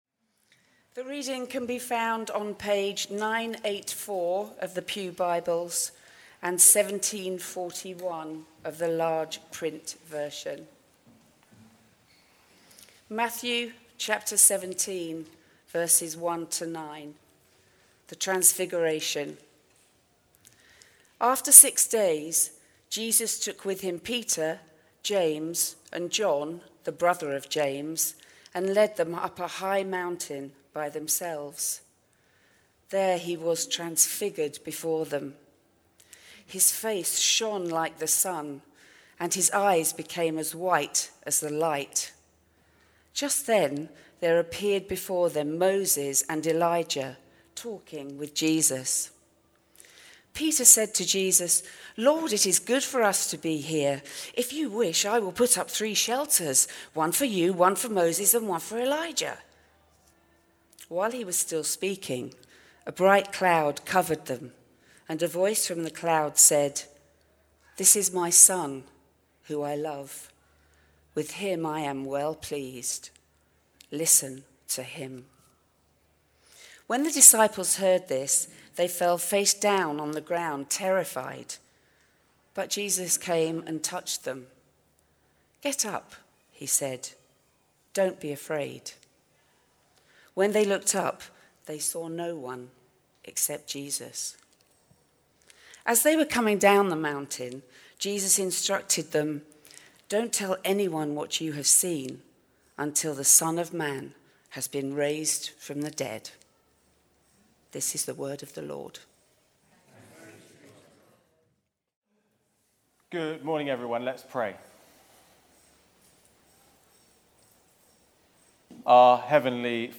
Theme: Sermon